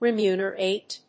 音節re・mu・ner・ate 発音記号・読み方
/rɪmjúːnərèɪt(米国英語), ɹɪˈmjuːnəɹeɪt(英国英語)/